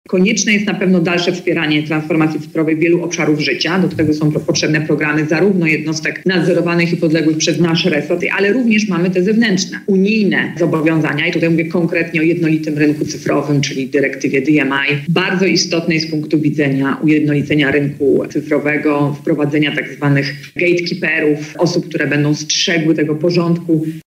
• mówi Podsekretarz Stanu, Pełnomocnik Rządu ds. Małych i Średnich Przedsiębiorstw Olga Semeniuk.